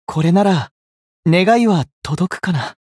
觉醒语音 これなら願いは届くかな 媒体文件:missionchara_voice_518.mp3